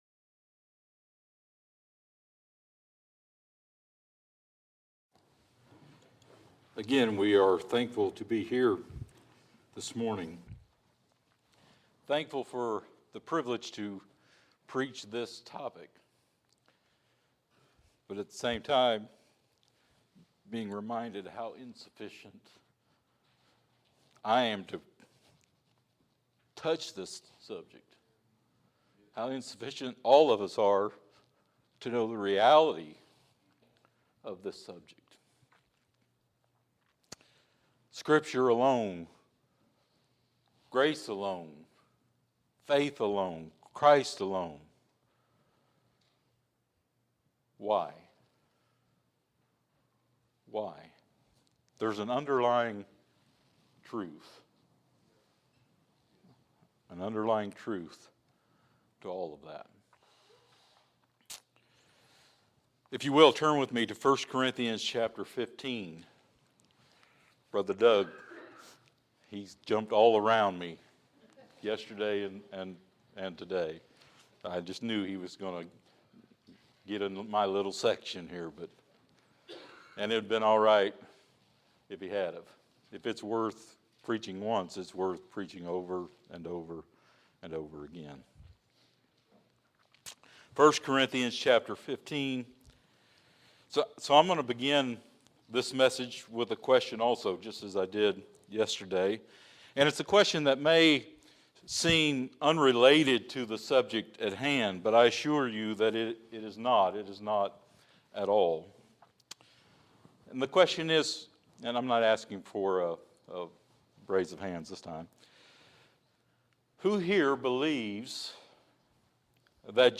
5 - God's Glory Alone | SermonAudio Broadcaster is Live View the Live Stream Share this sermon Disabled by adblocker Copy URL Copied!